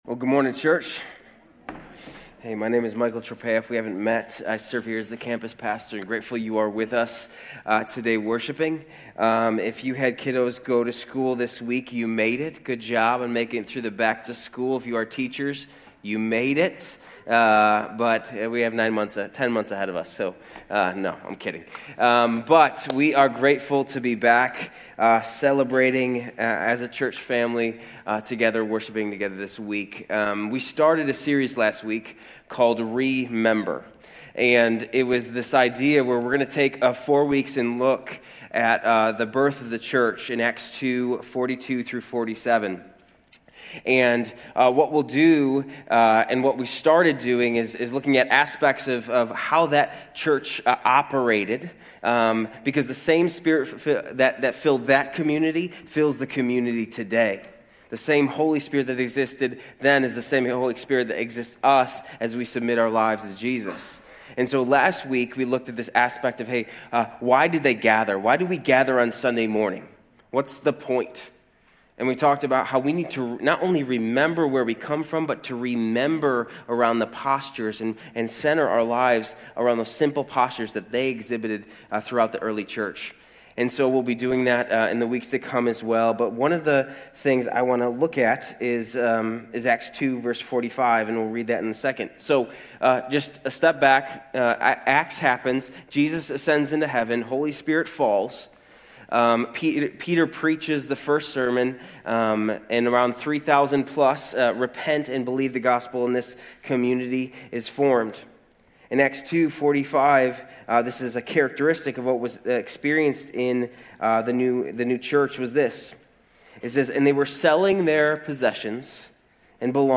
Grace Community Church University Blvd Campus Sermons 8_17 University Blvd Campus Aug 17 2025 | 00:30:48 1x 00:00 / 00:30:48 Subscribe Share RSS Feed Share Link Embed